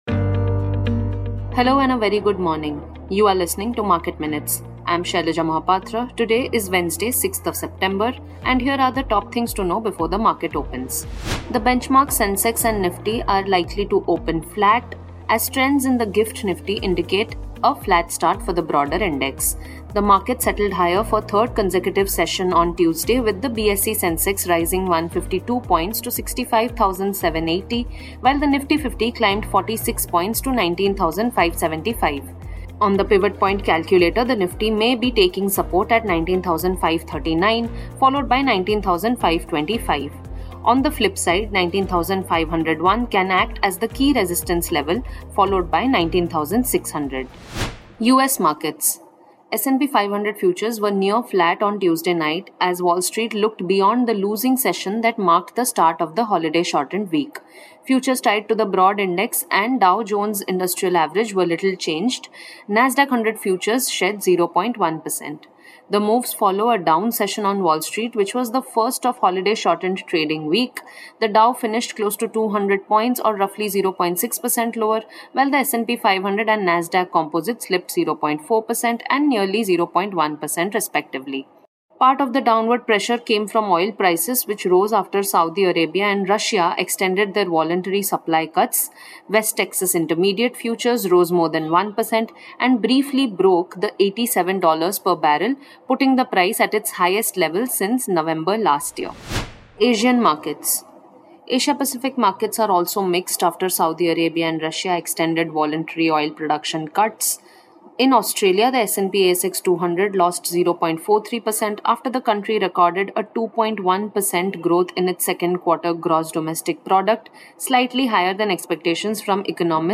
Market Minutes is a morning podcast that puts the spotlight on hot stocks, keys data points and developing trends.